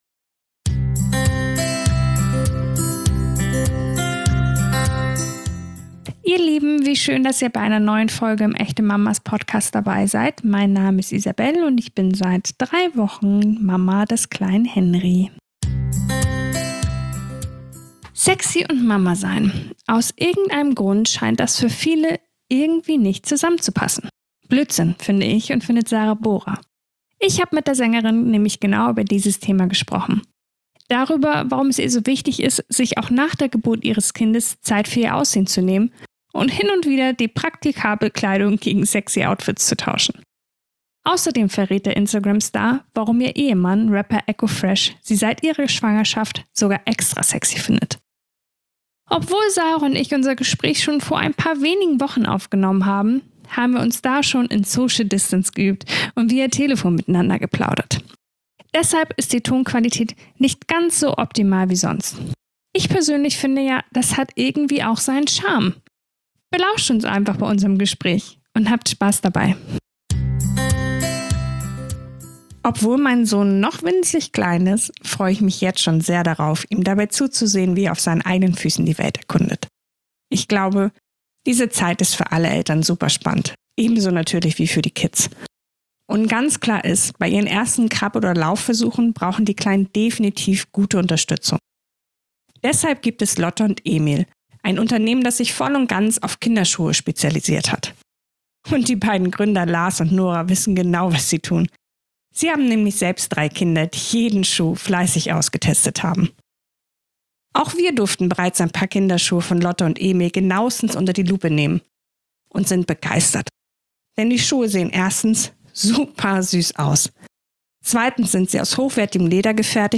Wie wohl sie sich in ihrem Körper fühlt und damit, ihn auch zu zeigen, verrät sie uns in einem offenen und echten Gespräch.